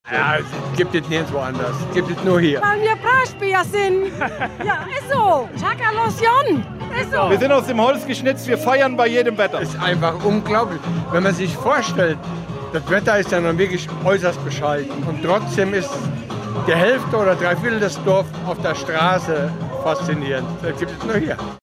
Empfang in Brachbach